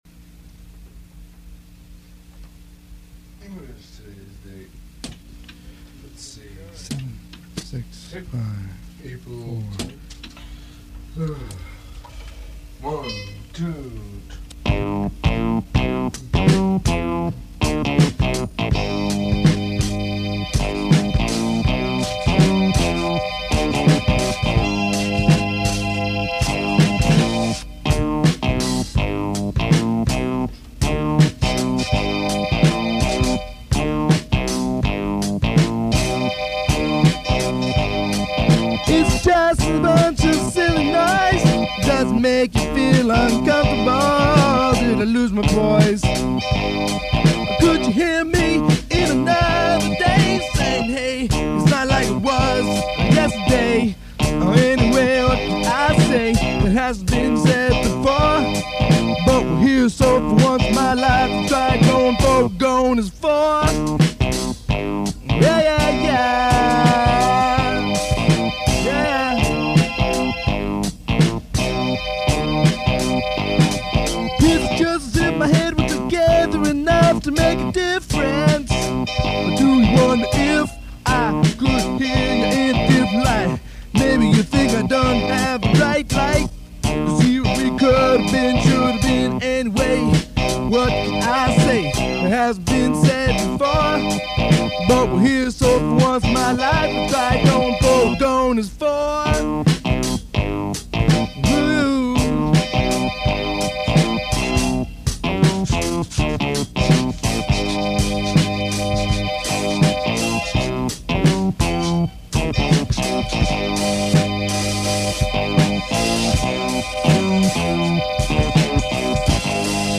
No chorus. No melody. A Latin rhythm of 3 against 2 but done as slow jazzy funk.  Spoken word on top, with a skat feel.
April 10th with lots of clicking sounds from the Tascam 4 track overdubbing.